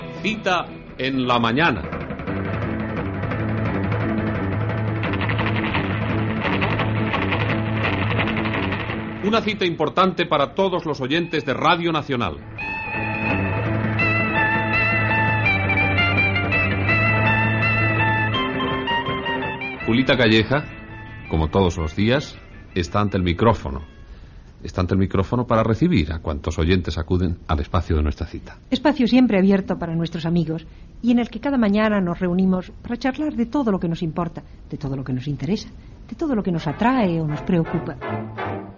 Careta i presentació inicial del programa.
Entreteniment